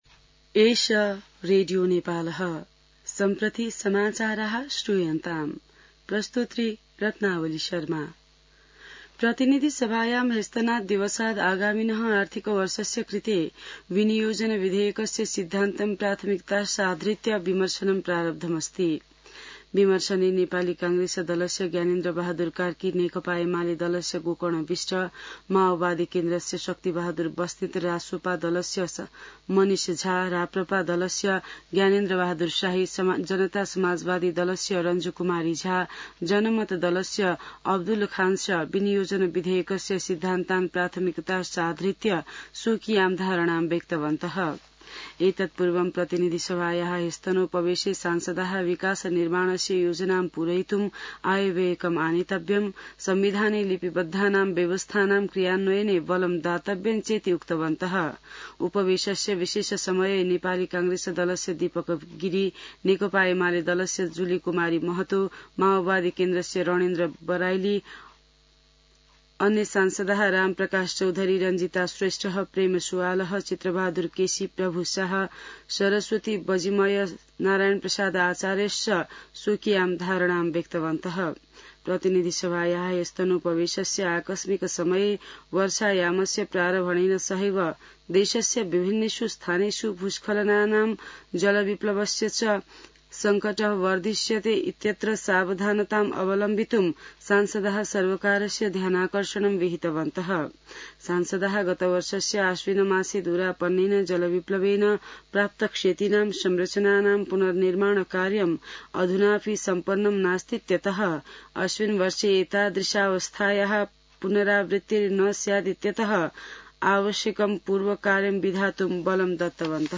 संस्कृत समाचार : ३१ वैशाख , २०८२